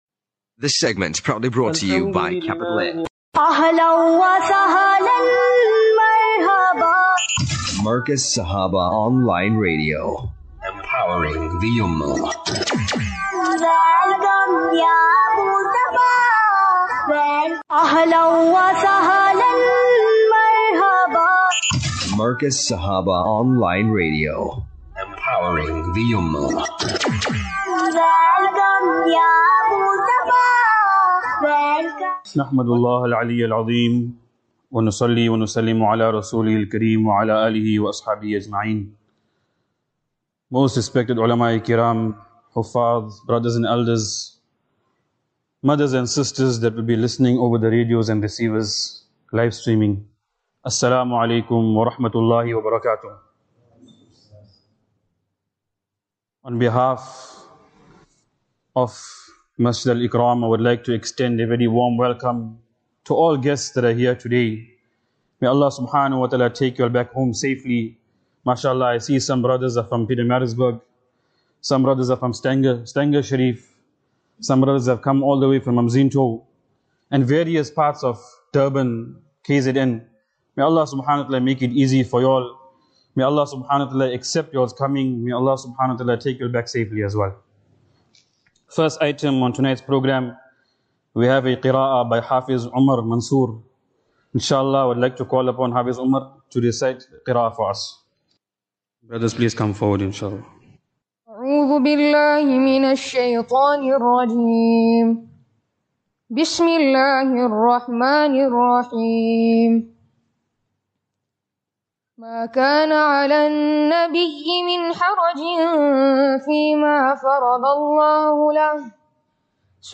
6 Sep 06 September 2025 - KZN Lecture Series
Lectures